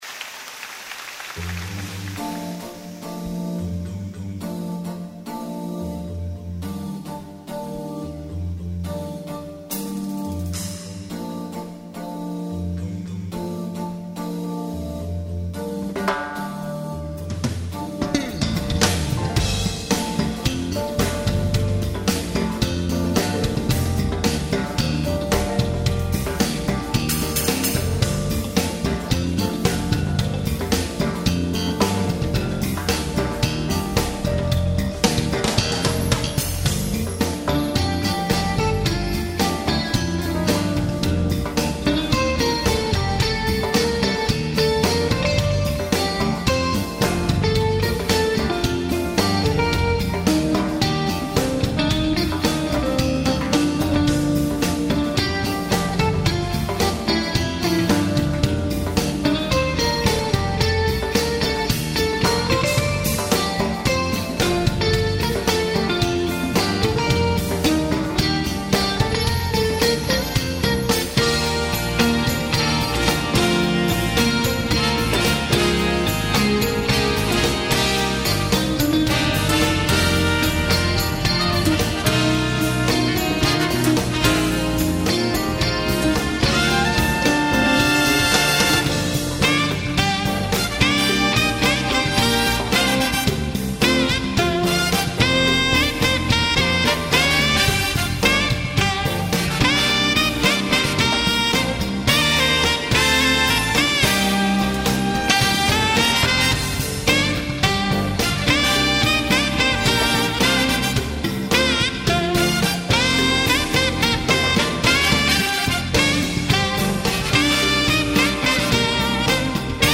색소폰 톤 예술로 잡았네요
브라스도 좋고 퍼커션도 굿입니다